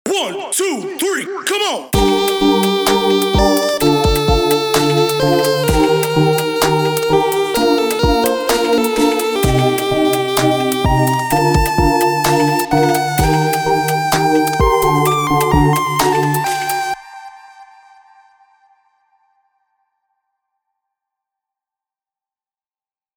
שימו לב: צירפתי כאן רצף אקורדים פשוט יחסית, האתגר שלכם הוא לעשות איתו מה שאתם רוצים ולצרף כאן, זה יכול להיות דרופ אלקטרוני, זו יכולה להיות מוזיקה רוק/פאנק/רגאיי אקוסטית, ואפילו מוזיקה קלאסית ומוזיקת ג’אז… הטמפו הוא 128 BPM, והסולם הוא GM(סול מינור) החוק הוא רק אחד: אתם חייבים להשתמש בהקלטה שצירפתי, גם בווקאל בהתחלה…